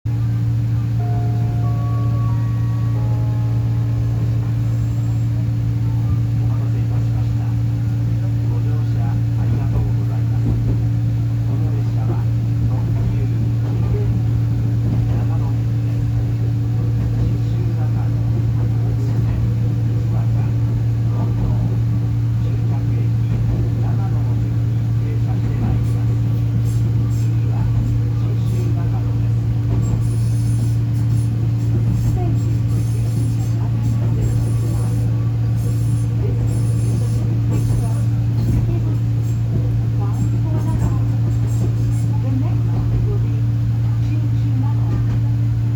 ・1000形車内放送
湯田中発車 次は信州中野
2100系と車内放送はアナウンサー、チャイムともども共通しており、小田急時代にはありえなかったJR東タイプの車内チャイムが流れます。